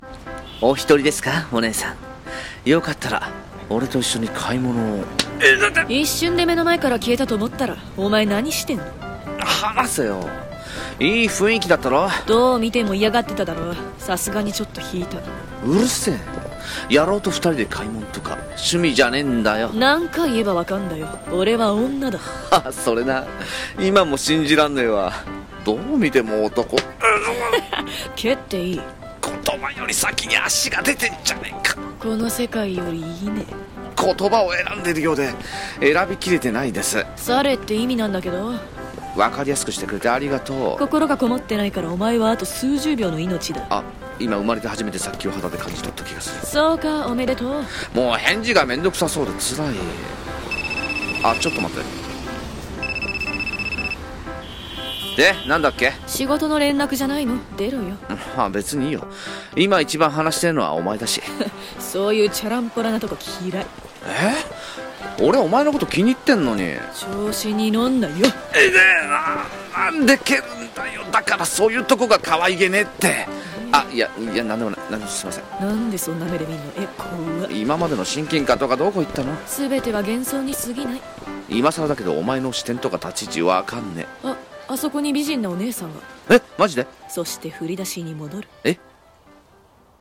【声劇】『無限ループ』ギャグのようななにか